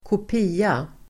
Uttal: [²kop'i:a]